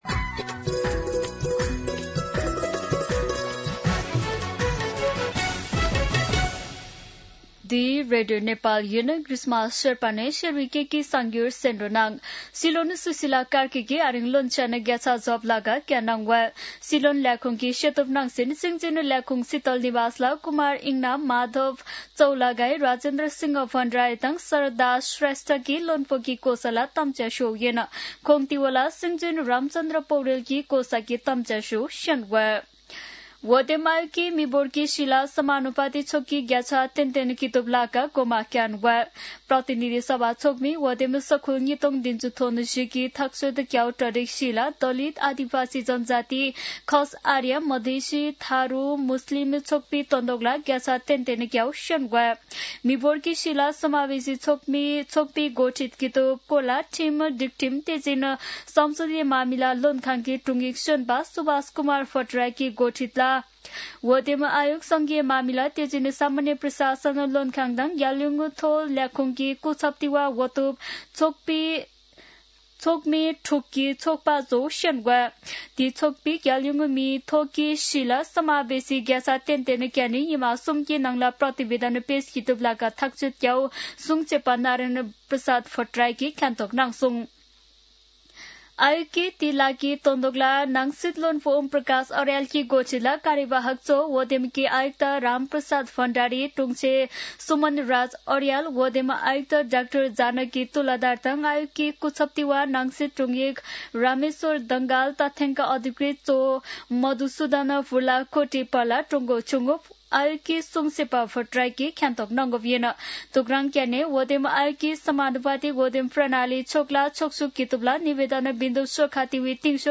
शेर्पा भाषाको समाचार : २६ मंसिर , २०८२
Sherpa-News-26.mp3